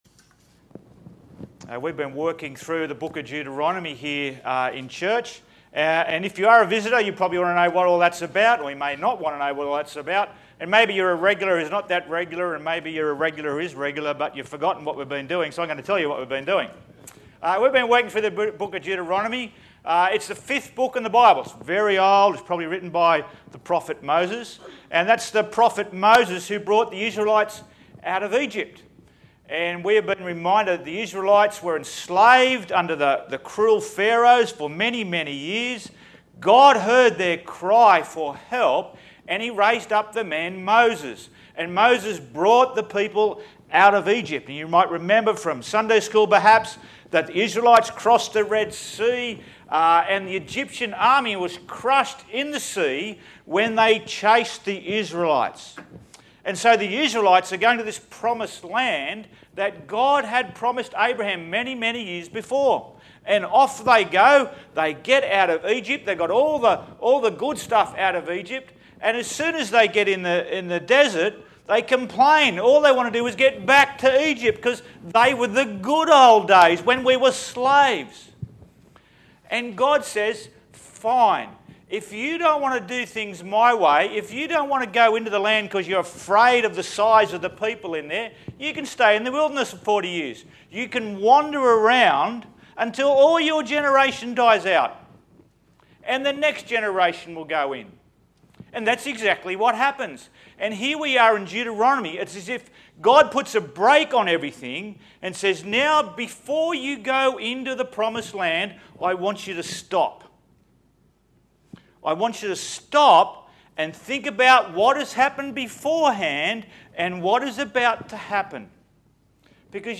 SERMON – The Joy of Generosity